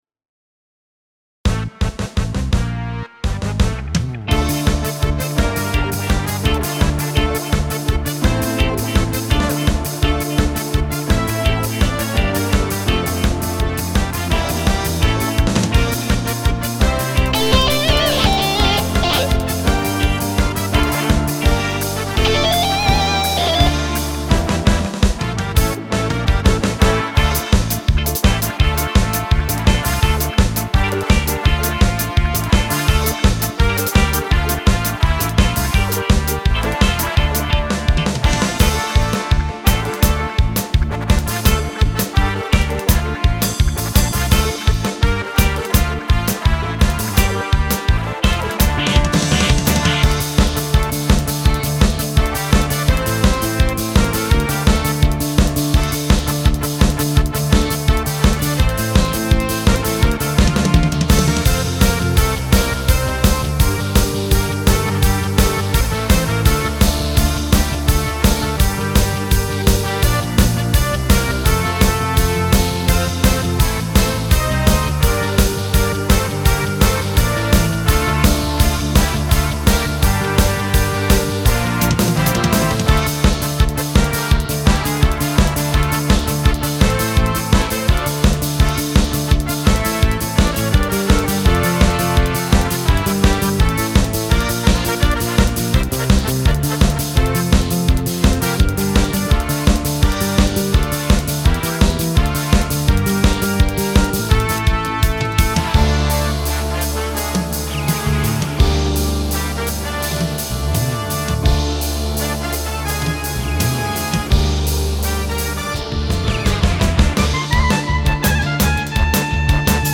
יש פה דוגמה למקצב רוק מתוך הסט שלי אשמח להערות/הארות וחוות דעת ROCK.MP3
בגדול נשמע מעולה ומאוזן, הדבר היחיד שקצת הציק לי זו הגיטרה החשמלית (הדיסטורשן). התפקיד שלה נכון, רק שהיא יותר נוכחת בתדרים הגבוהים, מאשר באזור הטבעי שלה - התדרים האמצעיים. מה שגורם לה להרגיש מרוחקת, כמו כששומעים דרך טלפון.